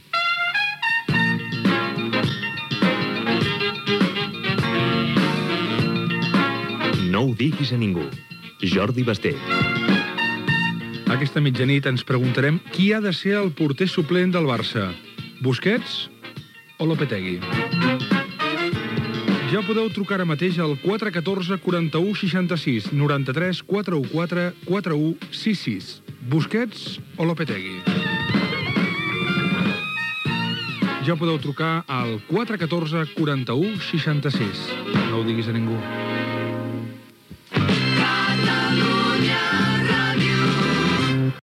Promoció del programa invitant a la participació telefònica per opinar. Indicatiu de l'emissora